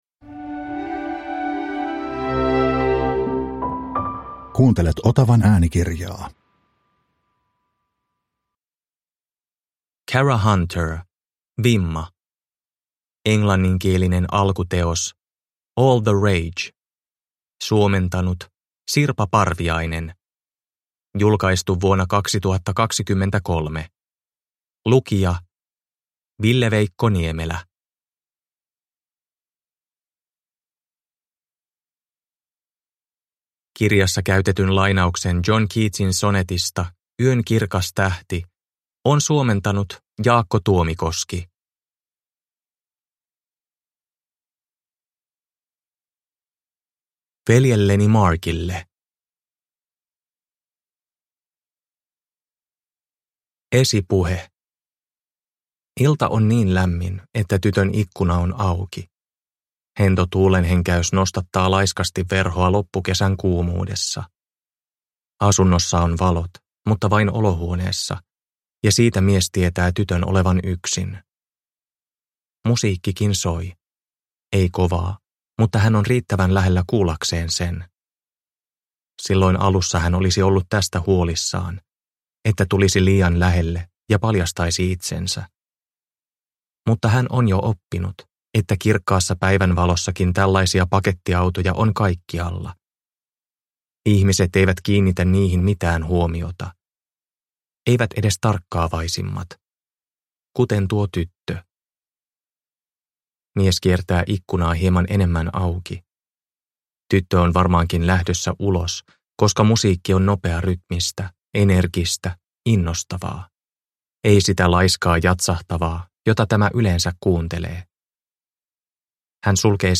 Vimma – Ljudbok